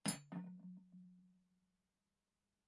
餐具声音 " 大叉子4
Tag: 餐具